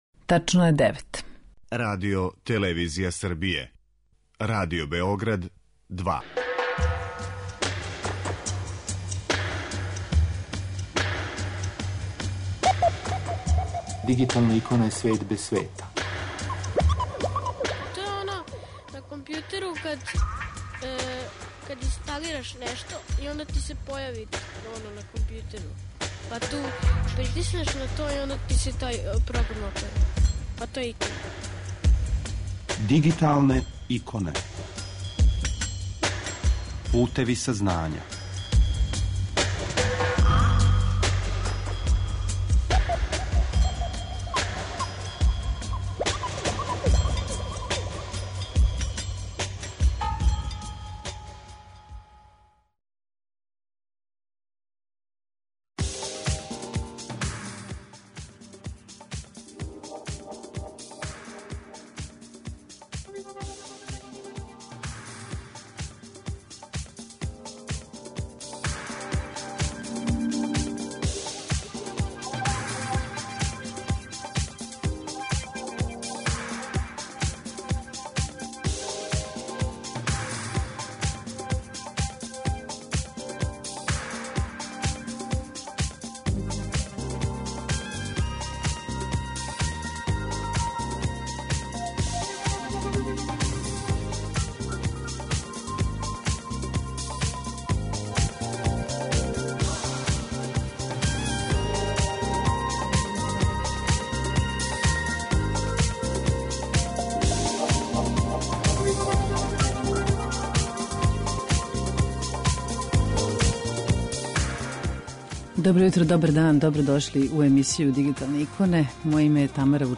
Са нама уживо